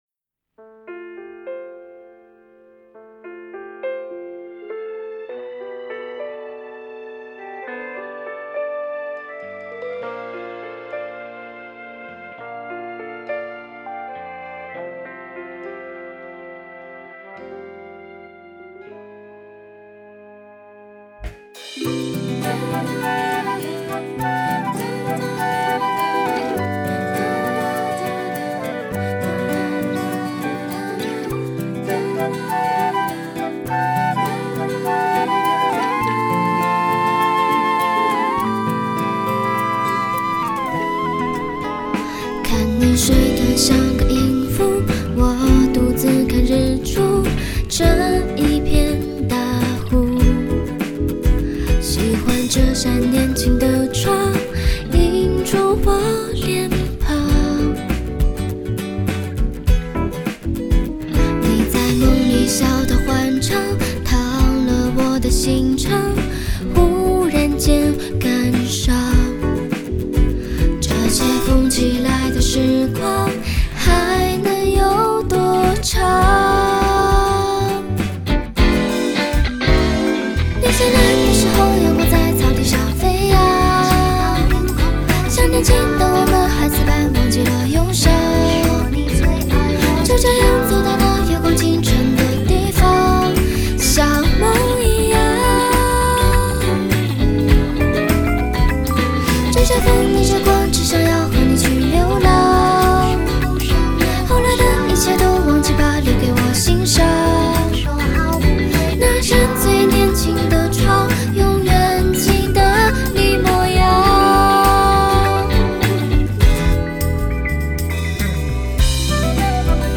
曲风：民谣